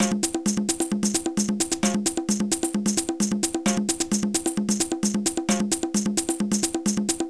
/ The Best of Mecomp Multimedia 1 / Mecomp-CD.iso / samples / octabeats / latin-(105\05-bpm) (.mp3) < prev    next >
B2_LoBongo
B2_HiBongo
Shaker2
B2_HiBongo+Marcacas+HH
LoBongo+Shaker